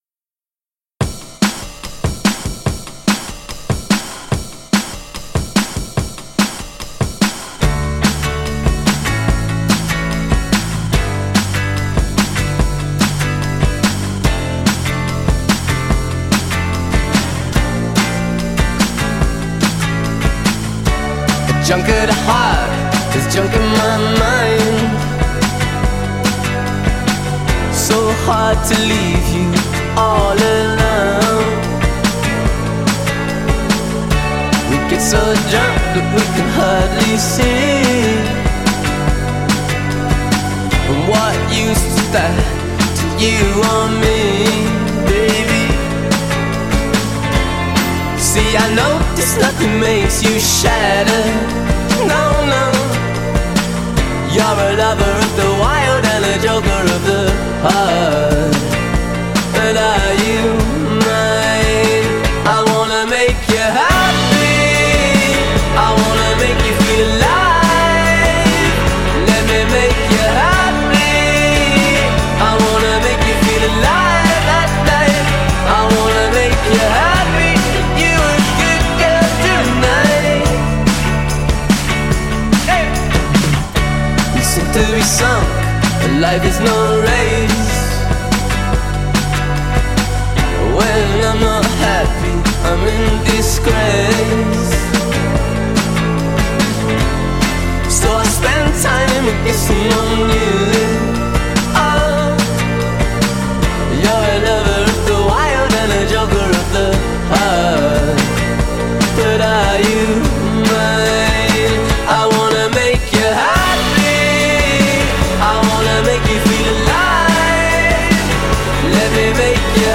infectious, Kinks-inflected garage-y power pop